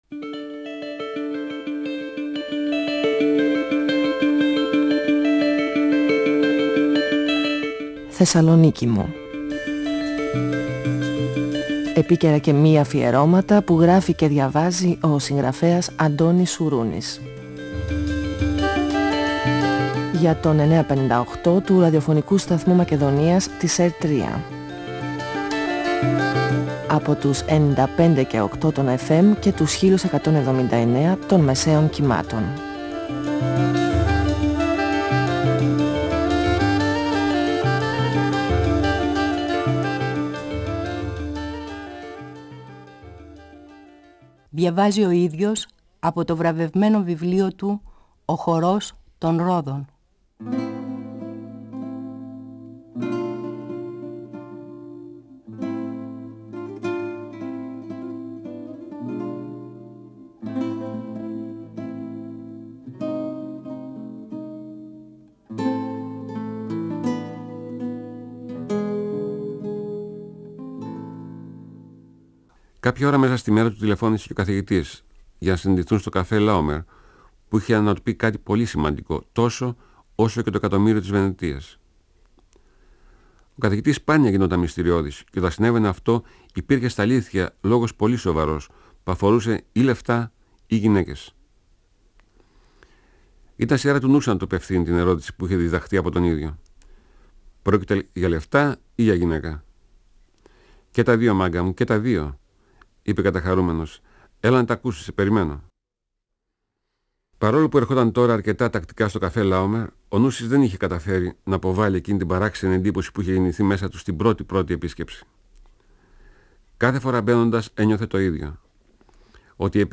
Ο συγγραφέας Αντώνης Σουρούνης (1942-2016) διαβάζει από το βιβλίο του «Ο χορός των ρόδων», εκδ. Καστανιώτη, 1994. Ο Καθηγητής ανακοινώνει στον Νούση ότι τον έχουν προσκαλέσει στο «Χορό των Ρόδων» και ότι θα πάνε μαζί στο καζίνο τού Μόντε Κάρλο.